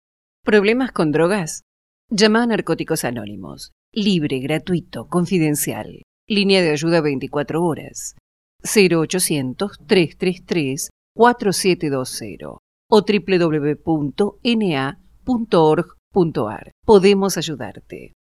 Descargas: Que es IP Vídeo Que es la Información Pública Intro IP Historia Resumida Un Recurso en su Comunidad Manual I.P. Modelos Carta Medios de Comunicación Audio para radios N.A. Spot Publicitario 2016
na_-_radio_version_1.mp3